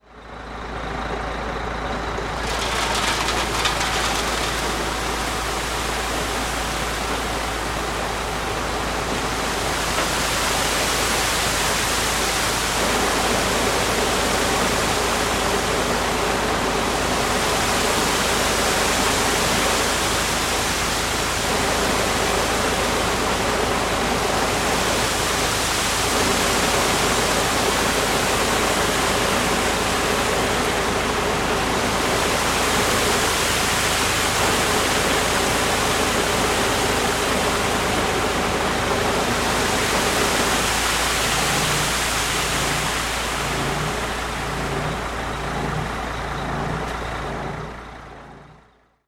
Качество записей проверено – никаких лишних шумов, только чистый звук техники.
Звук заливки бетона в автобетоносмеситель